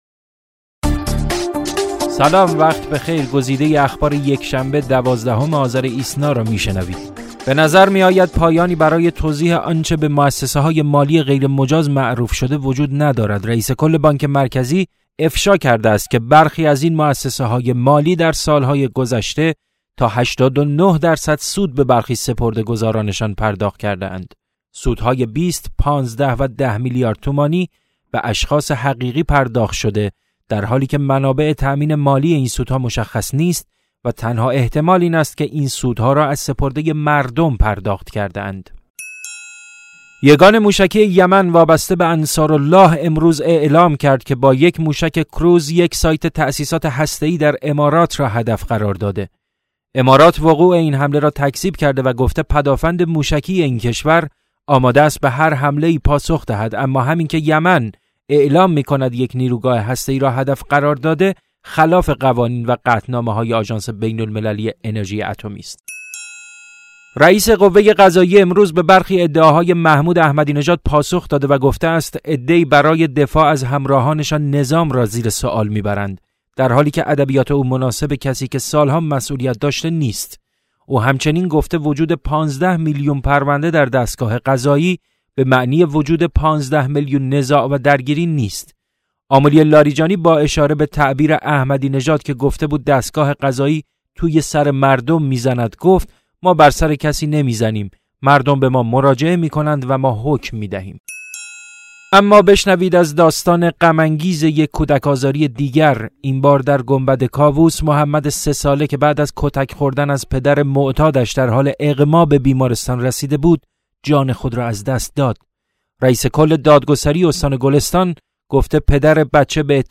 صوت / بسته خبری ۱۲ آذر ۹۶